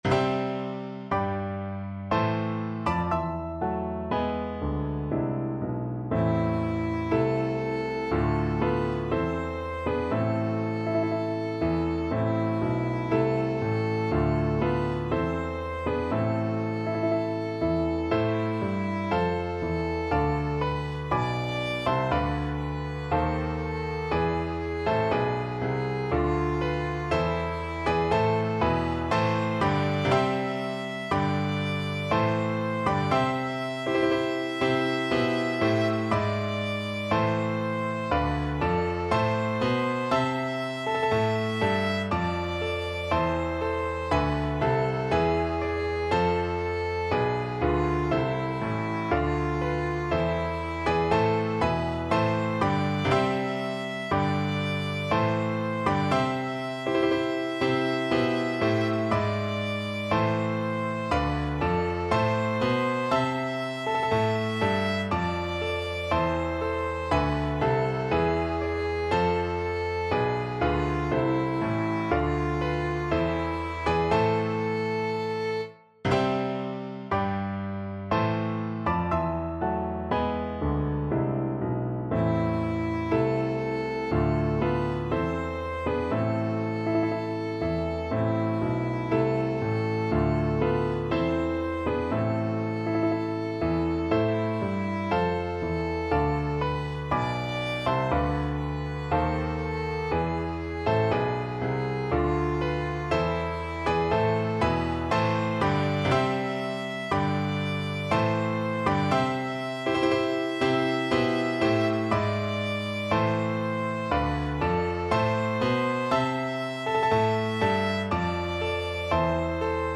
Traditional Trad. Mila Rodino (Bulgarian National Anthem) Violin version
Violin
C major (Sounding Pitch) (View more C major Music for Violin )
Andante maestoso =c.60
3/4 (View more 3/4 Music)
Traditional (View more Traditional Violin Music)